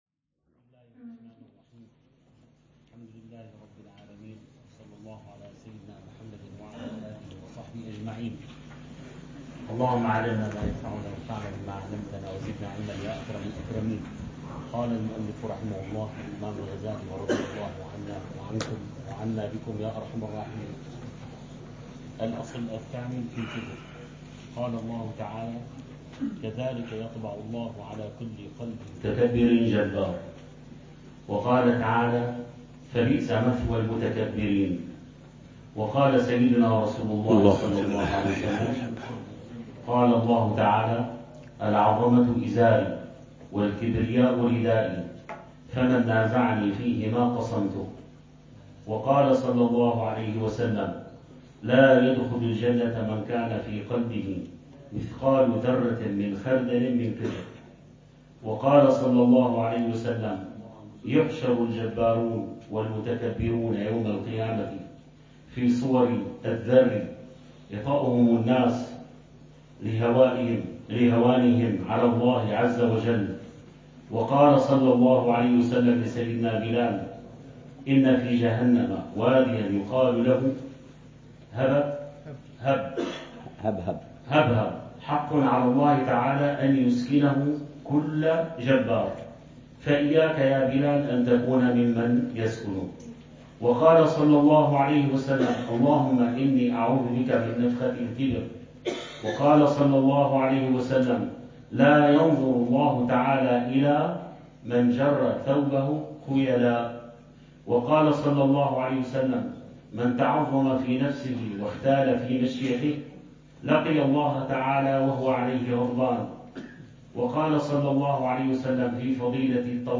الدرس ال28 في شرح الأربعين في أصول الدين: الكِبر والعُجب: حقيقة الشعور بالتفوق، علاماته في السلوك، ولماذا يحجب عن كل خُلق محمود؛ ثم مدخل العُجب